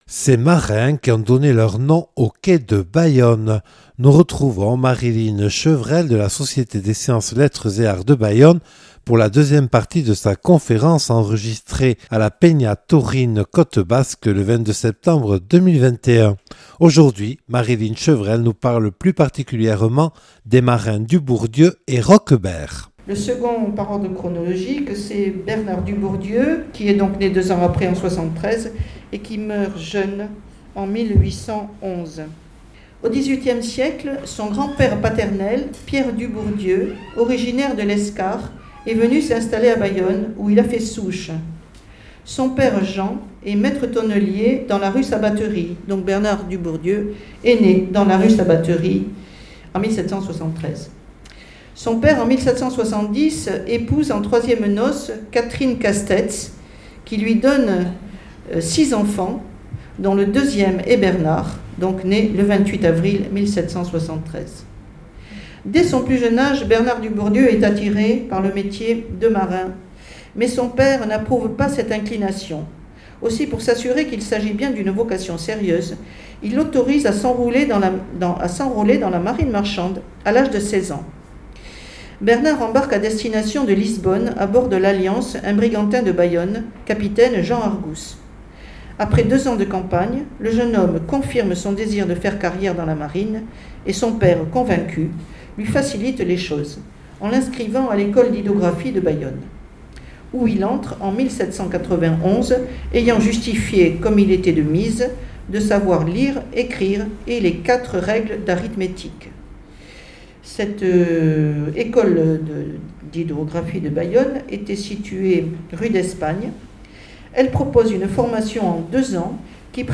(Enregistrée le 23/09/2021 lors de la conférence proposée par la Société des Sciences, Lettres et Arts de Bayonne).